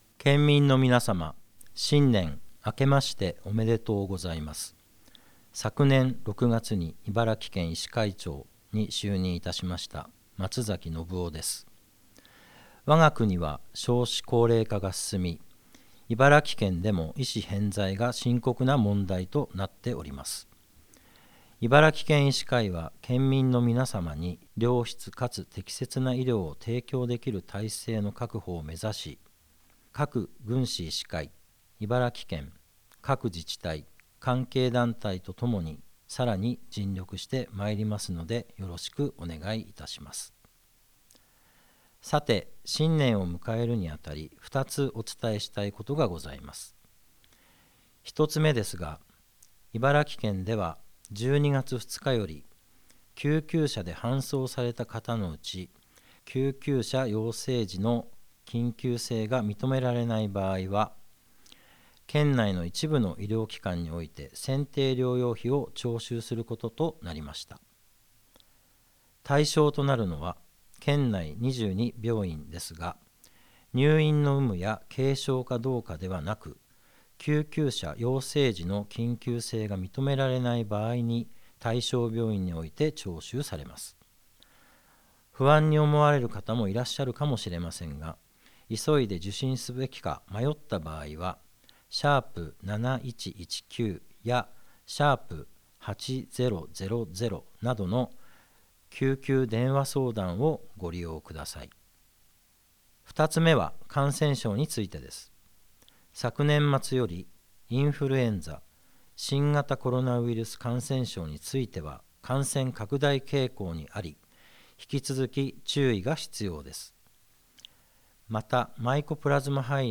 「２０２５年声の年賀状」